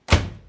Foley " RFX 关闭阀盖
描述：声音记录的发动机关闭。
标签： 汽车 关闭 关闭 发动机盖
声道立体声